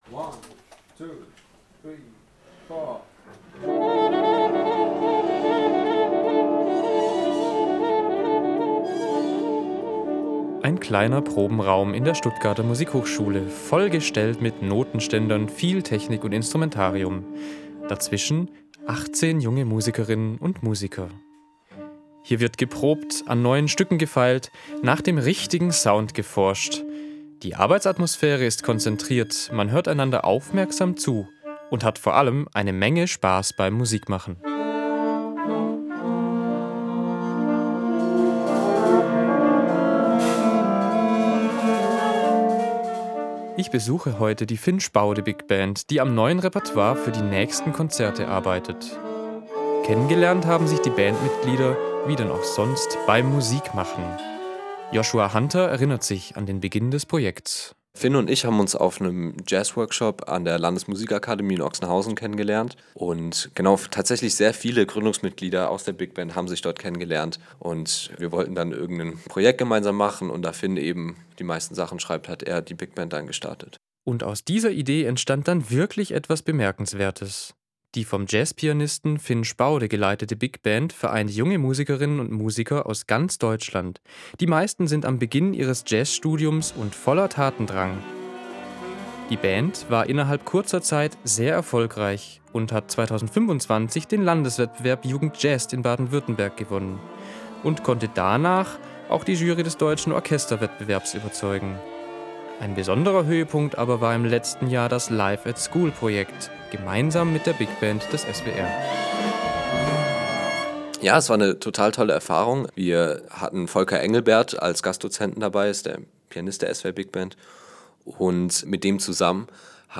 Musikthema